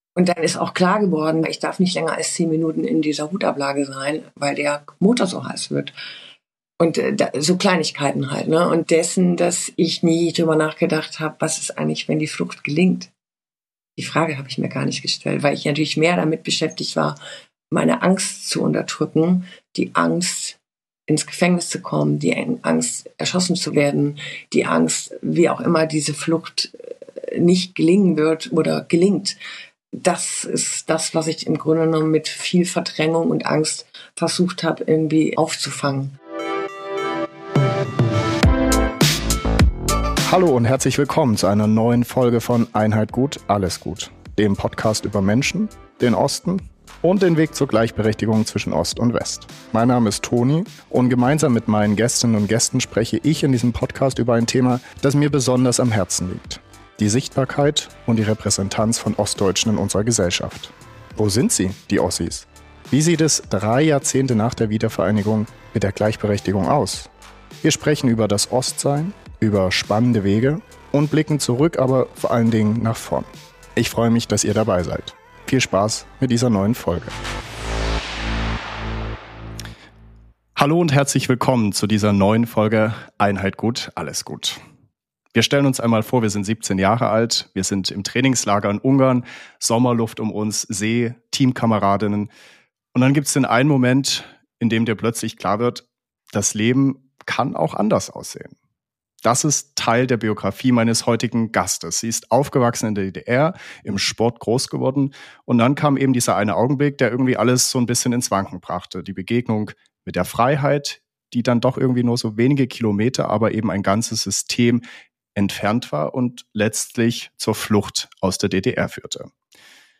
Ein sehr persönliches Gespräch über Freiheit, Mut und Wertvorstellungen.